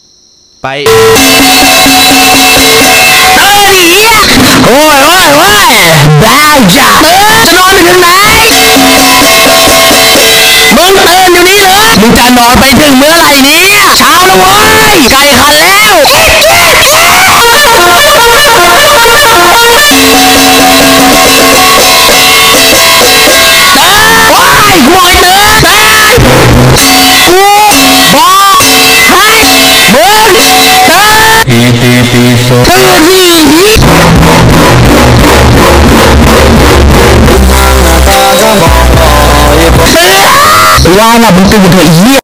เสียงนาฬิกาปลุกดังๆ
เสียงนาฬิกาปลุก iPhone 16 เสียง อย่าๆๆๆ มีม
หมวดหมู่: เสียงเรียกเข้า